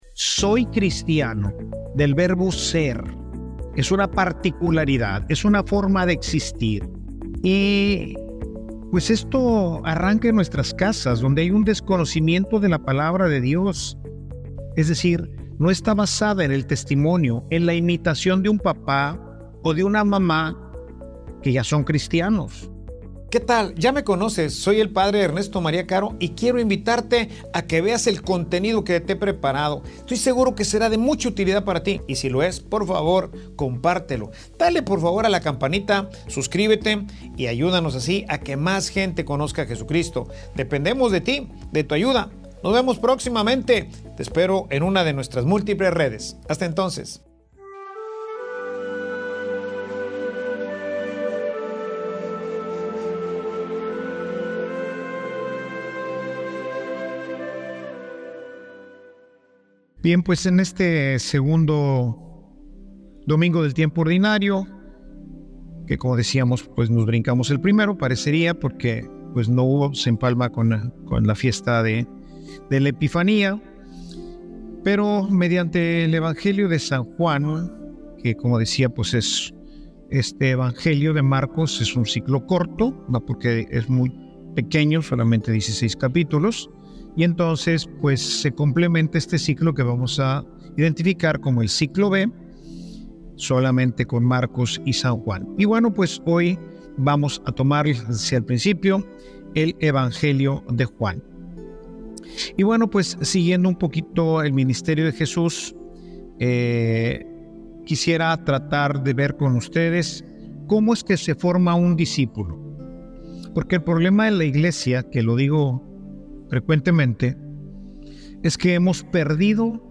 Homilia_El_cristiano_es_un_imitador.mp3